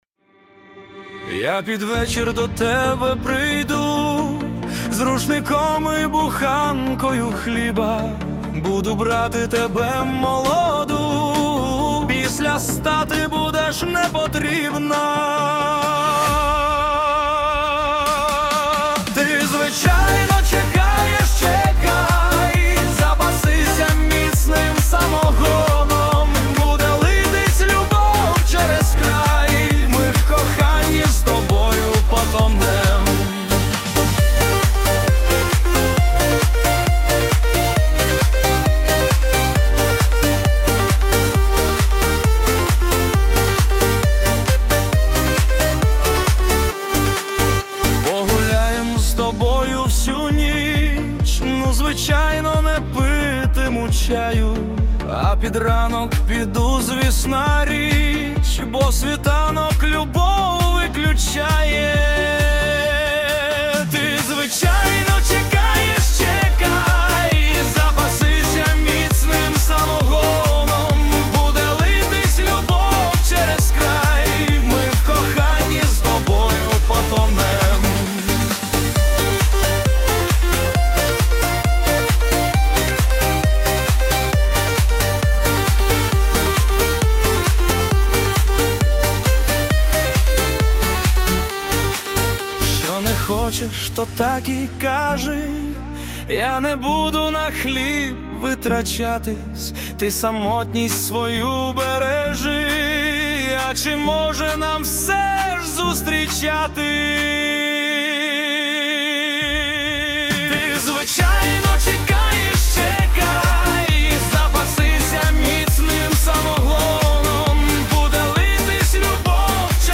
Пісня веселенька, сподобалася.